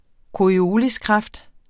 Udtale [ koɐ̯iˈoːlis- ]